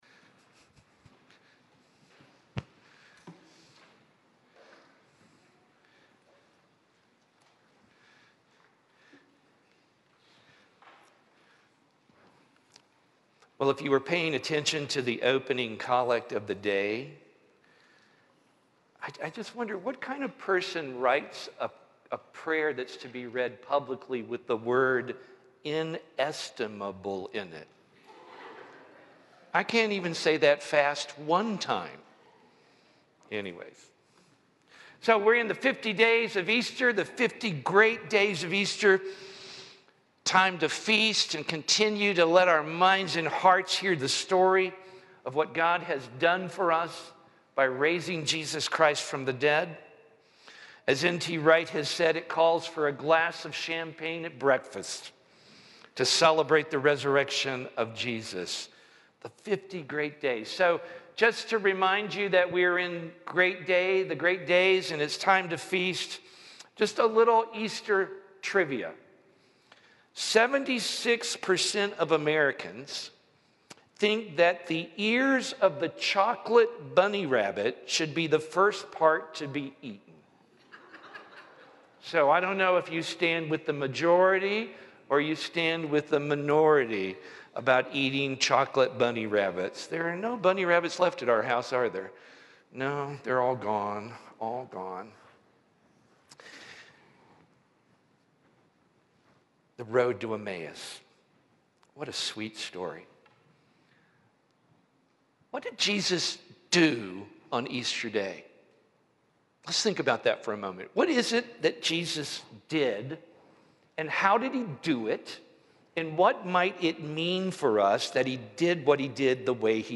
Sermon FMG 042323 - Apostles Anglican Church - Lexington, KY